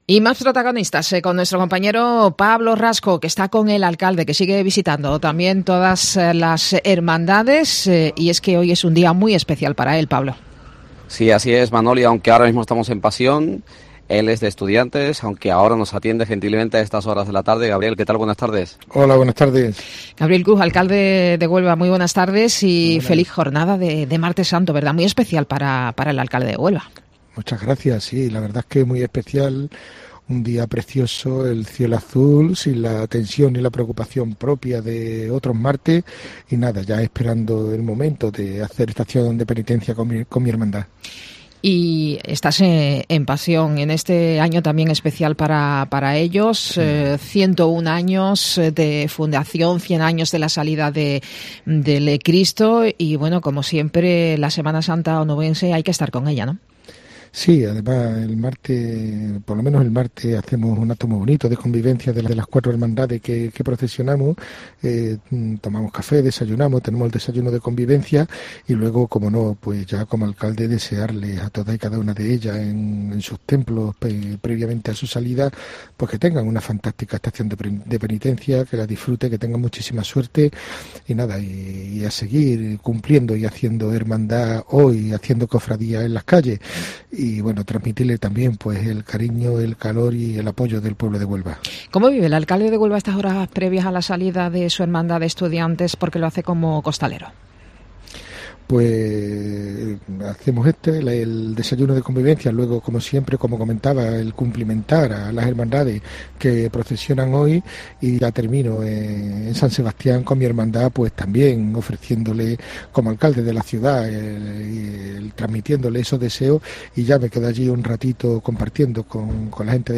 AUDIO: Alcalde de Huelva en la Iglesia de San Pedro